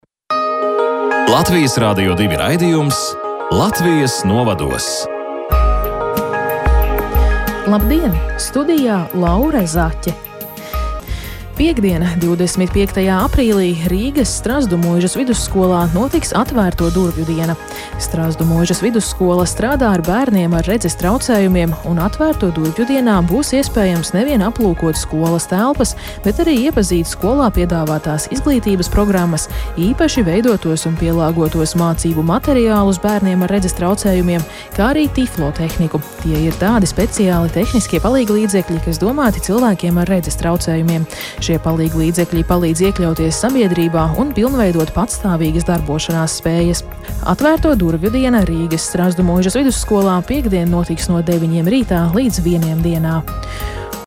Informācija izskanēja 22. aprīlī raidījumā “Latvija novados”.